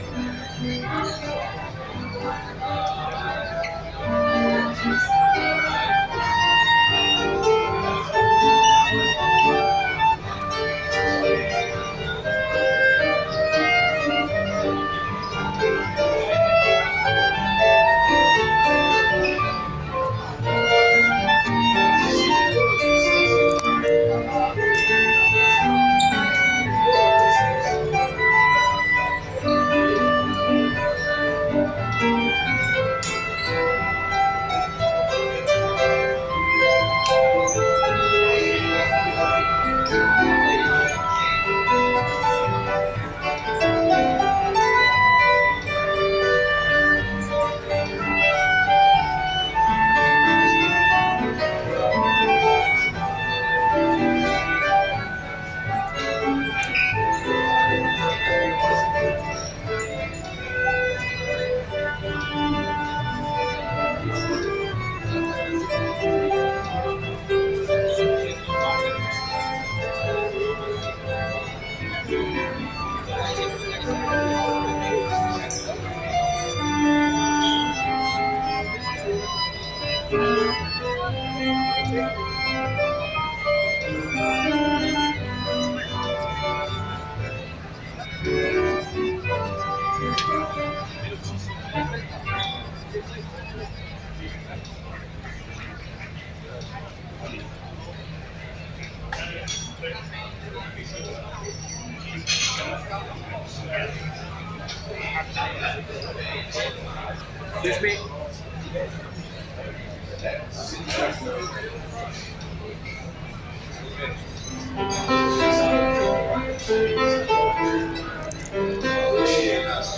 Italia, Venecia, Caffé Florian - Sonidos de Rosario
Plaza de San Marcos 57 10.30 hs. 21 de Julio 2025
oa-italia-venecia-caffe-florian.mp3